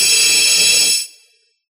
Ice5.ogg